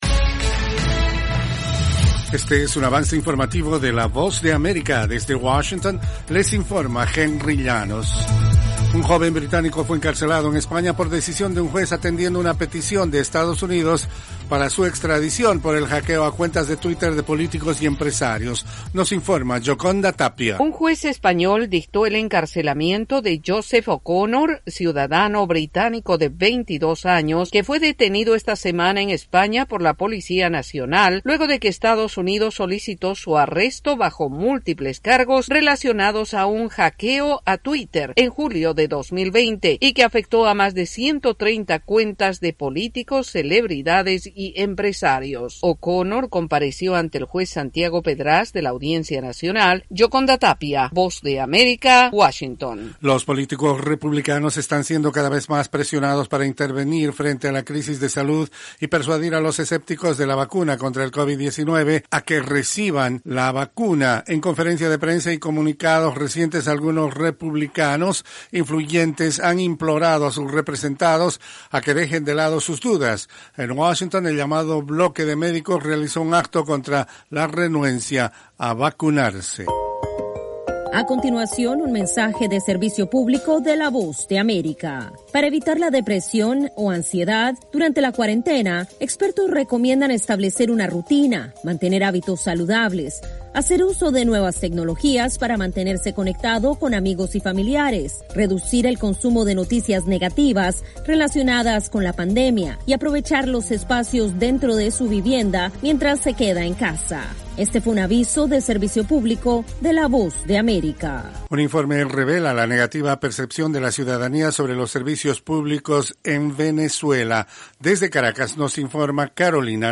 Cápsula informativa de tres minutos con el acontecer noticioso de Estados Unidos y el mundo.